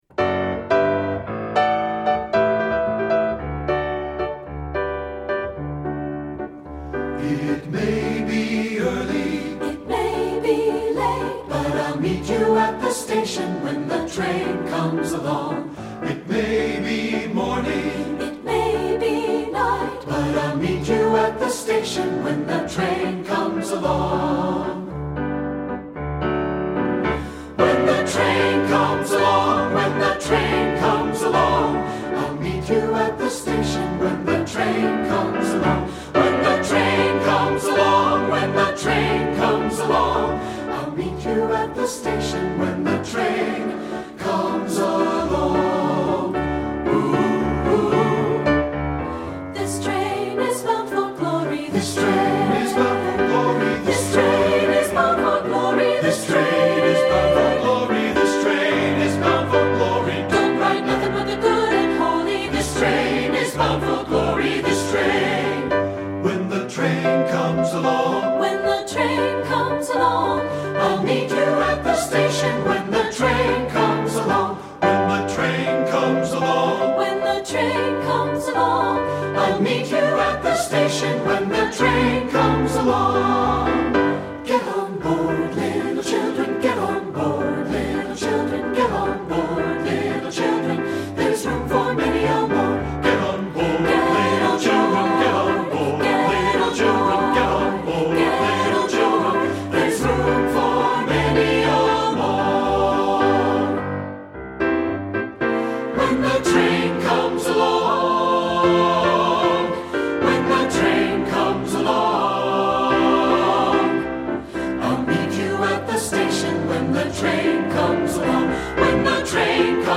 Composer: Traditional Spirituals
Voicing: SAB and Piano